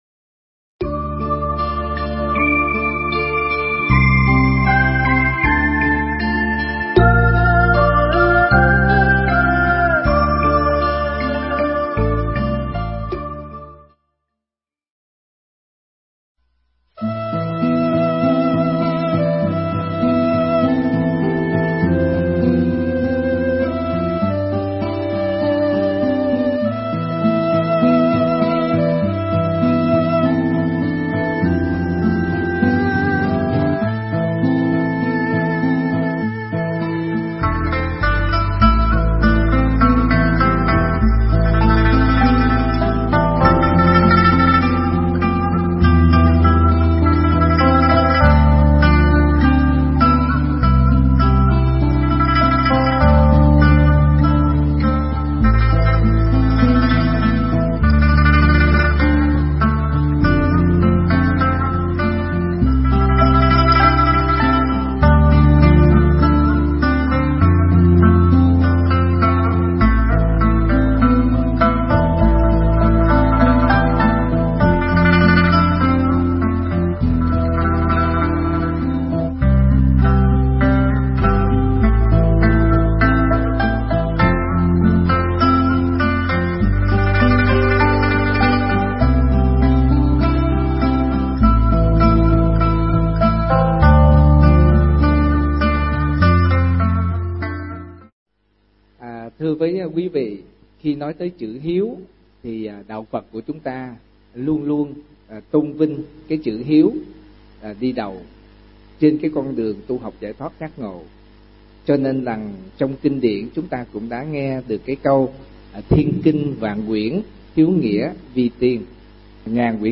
Nghe Mp3 thuyết pháp Ý Nghĩa Tri Ân Và Báo Ân
Mp3 pháp thoại Ý Nghĩa Tri Ân Và Báo Ân